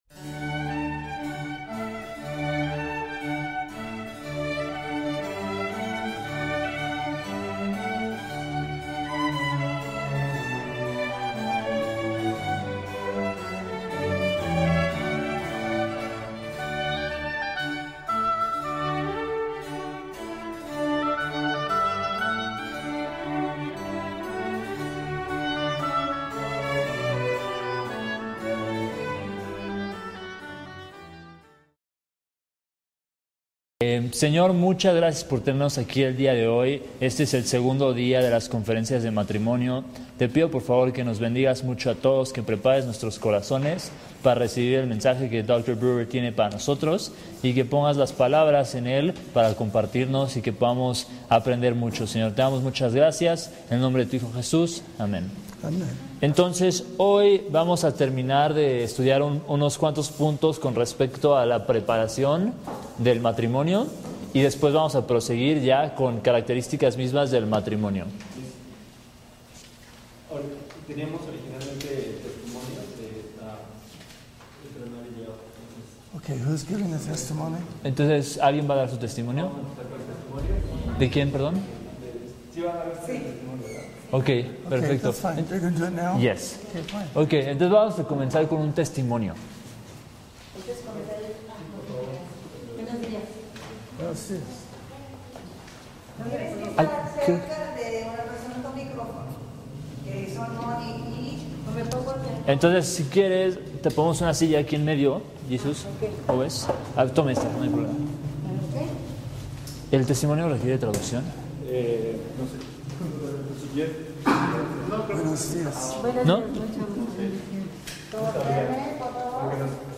Conferencia Matrimonio 4